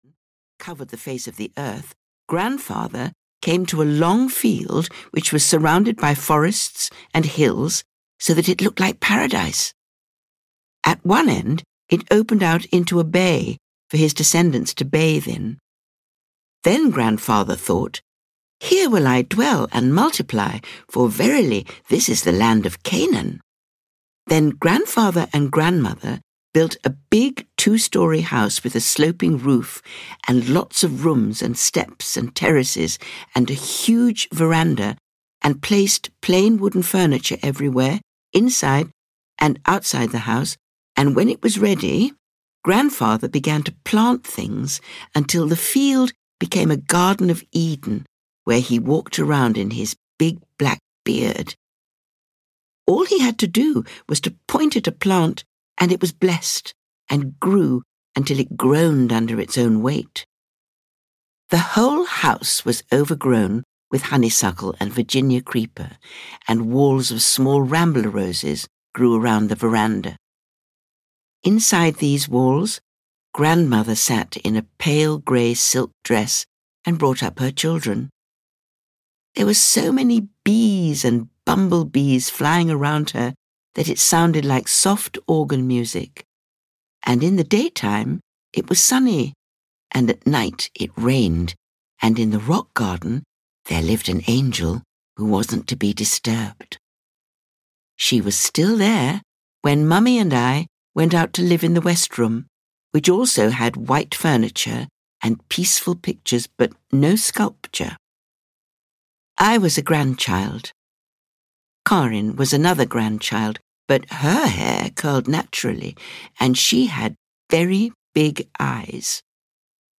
Sculptor's Daughter (EN) audiokniha
Audiobook Sculptor's Daughter written by Tove Jansson.
Ukázka z knihy
• InterpretSara Kestelman